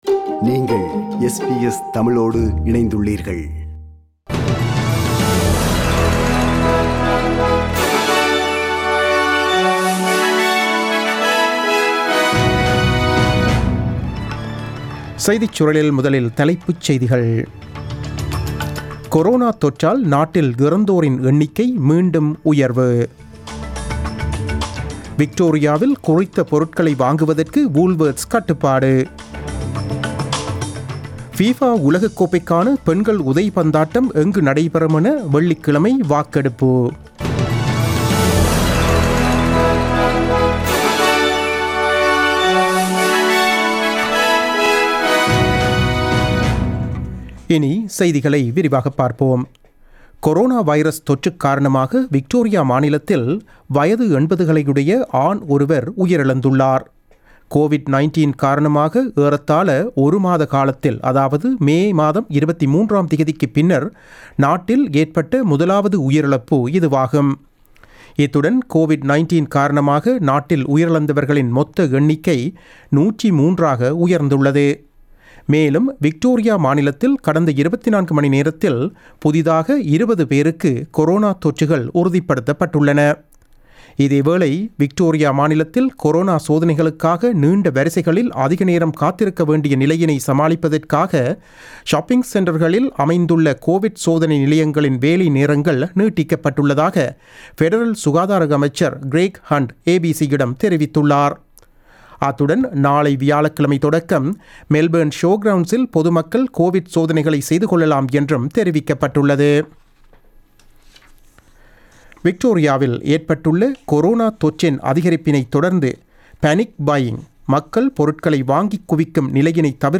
The news bulletin broadcasted on 24 June 2020 at 8pm.